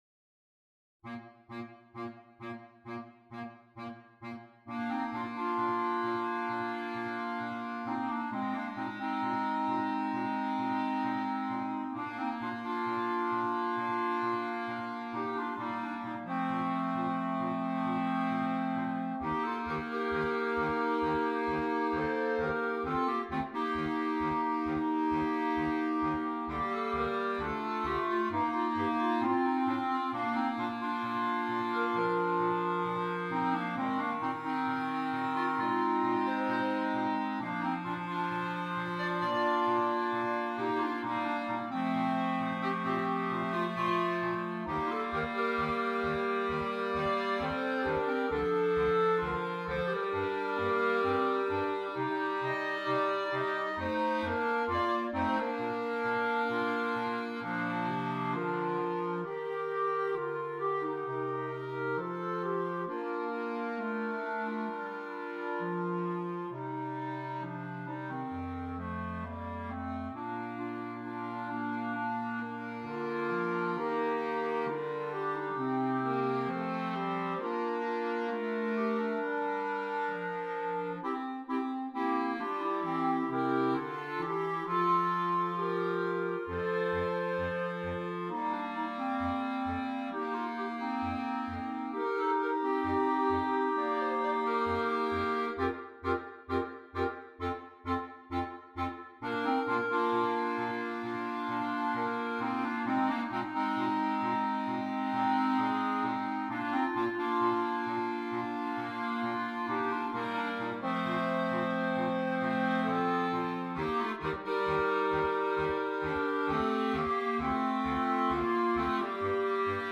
4 Clarinets, Bass Clarinet